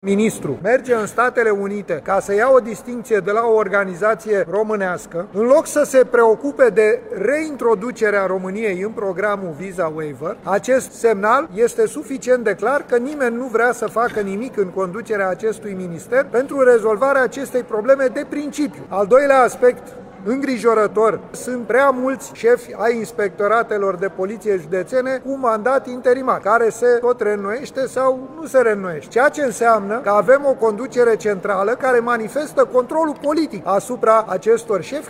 Liderul senatorilor AUR, Petrișor Peiu: „Ministrul merge în Statele Unite ca să ia o distincție de la o organizație românească, în loc să se preocupe de reintroducerea României în programul Visa Waiver”